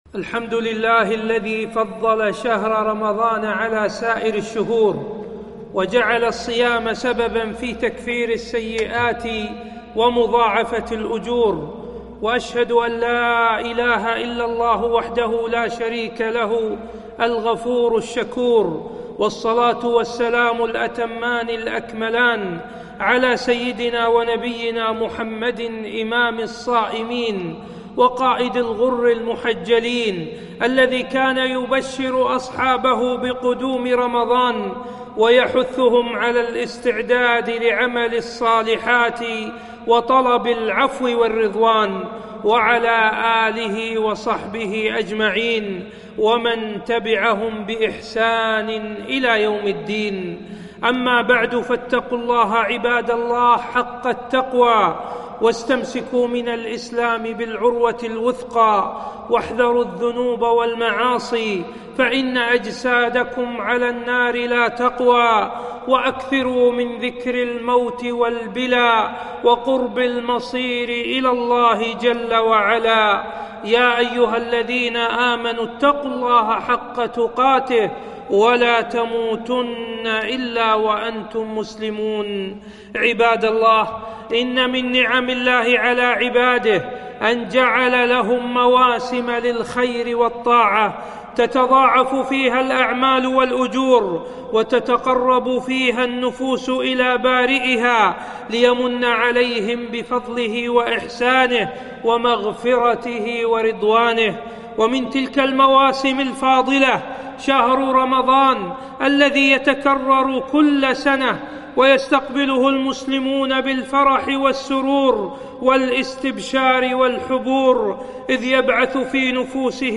خطبة - وقفات في استقبال رمضان